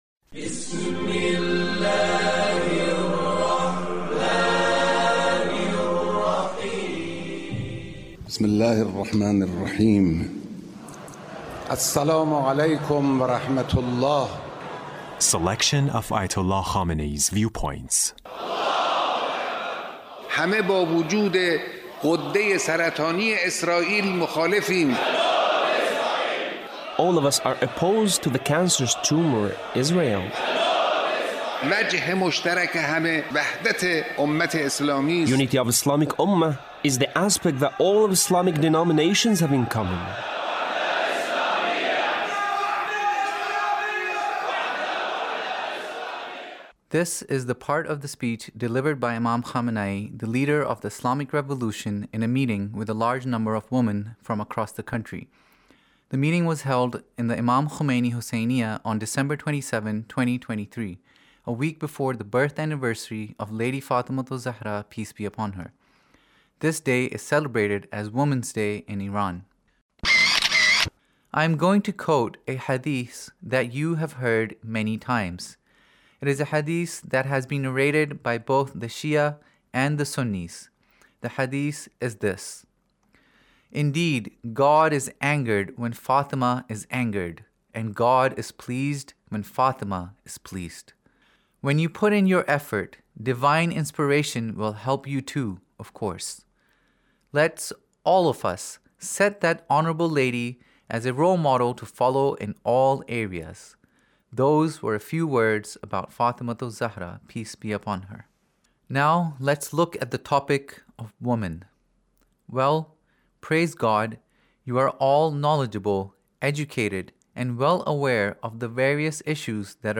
Leader's Speech about , in a meeting with Ladies